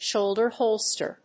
アクセントshóulder hòlster